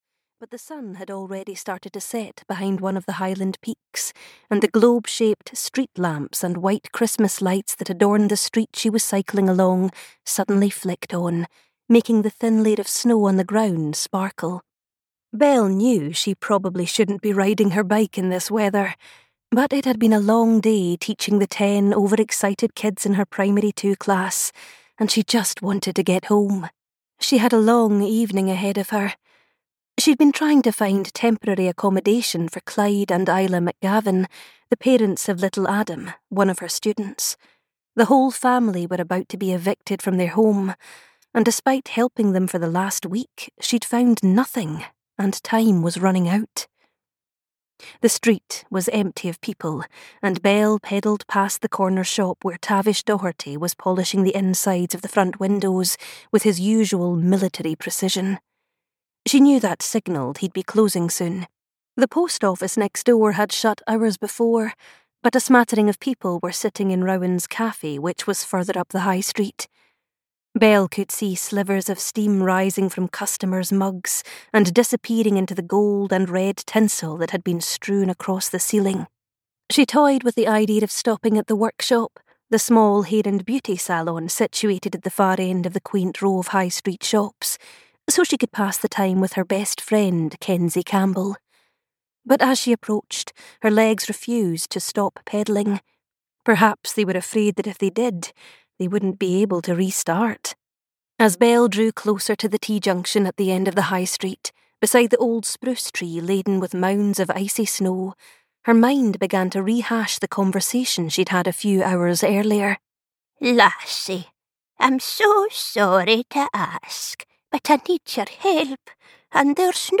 Christmas in the Scottish Highlands (EN) audiokniha
Ukázka z knihy